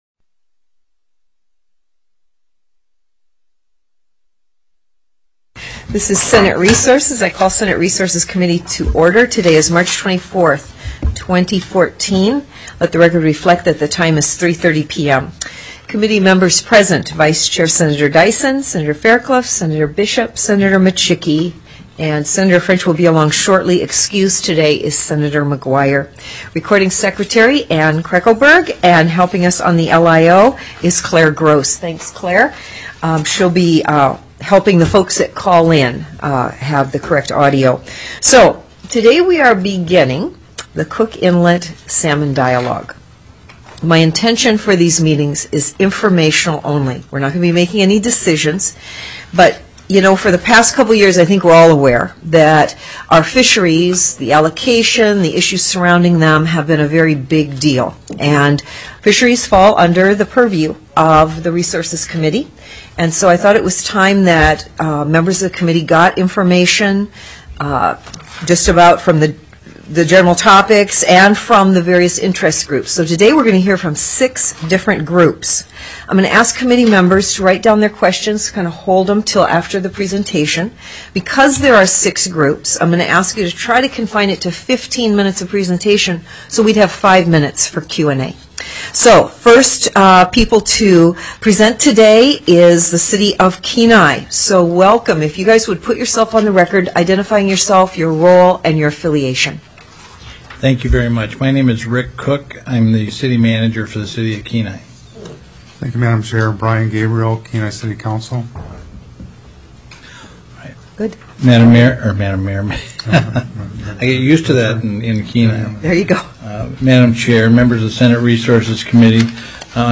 Upper Cook Inlet Salmon Dialogue TELECONFERENCED